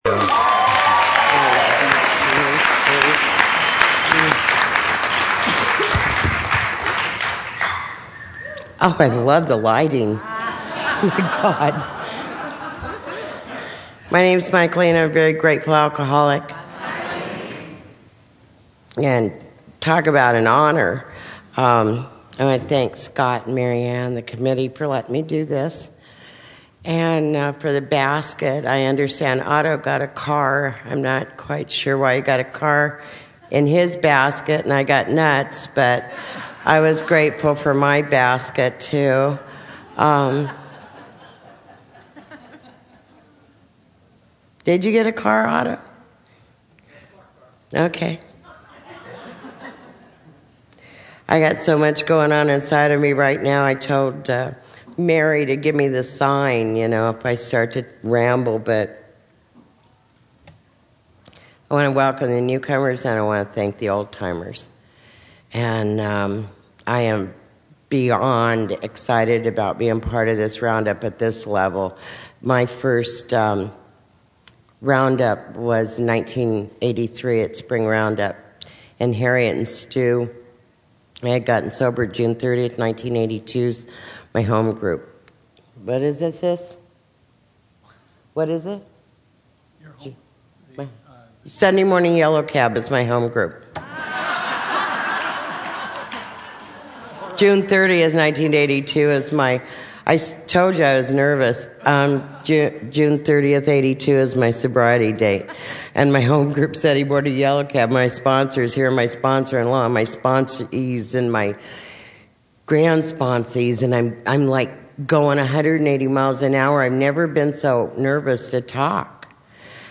San Diego Spring Roundup 2009